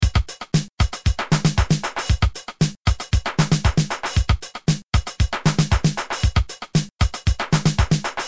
Tre trummaskiner, dom rætta svaren!
Alla ljud är samlingar och sequensade i Tunafish.
Att kalla 10 sek klipp med enbart trummor för "låtar" och "extremsunk" känns som en kraftig överdrift.